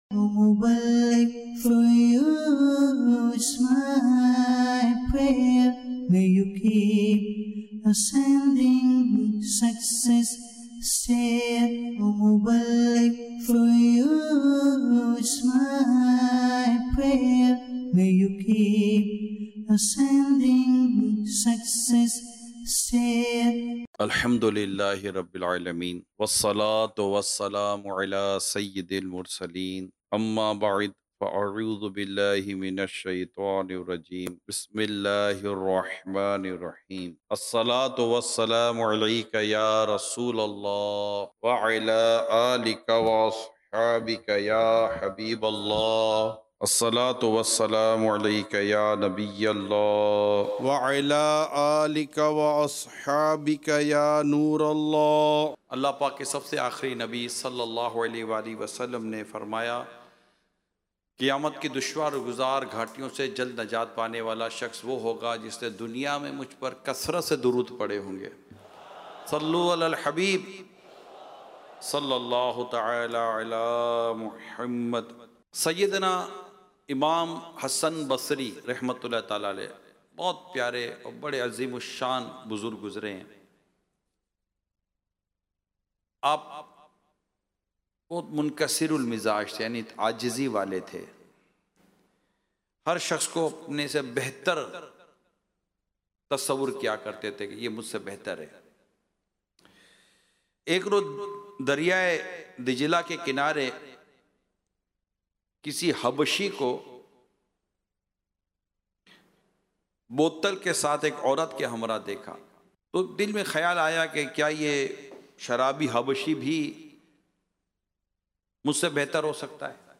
Sunnah Inspired Bayan - Sayyiduna Hasan Basri رحمۃ اللہ علیہ